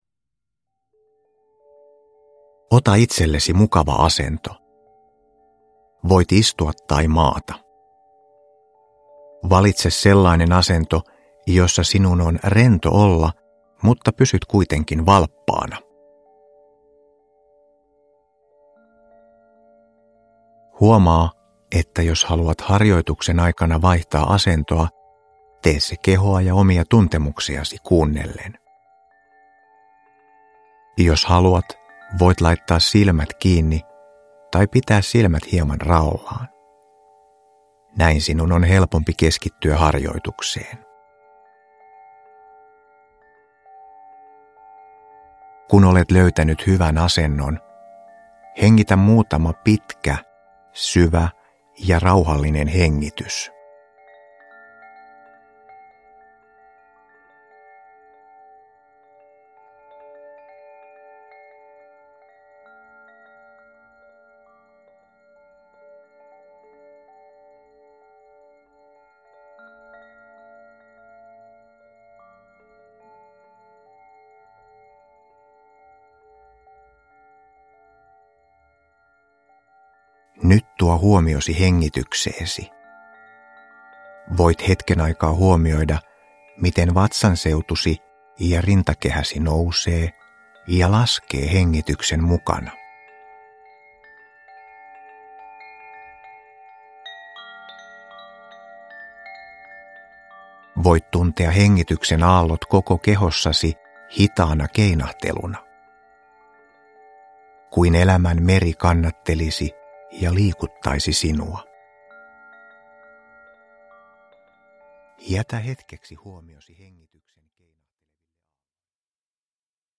Hektisen olotilan purku – Ljudbok – Laddas ner